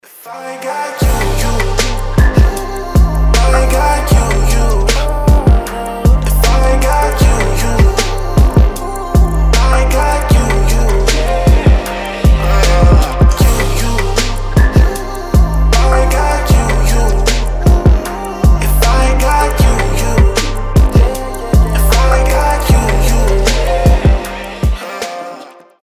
• Качество: 320, Stereo
мужской голос
Хип-хоп
атмосферные
мелодичные
спокойные
RnB
Chill Trap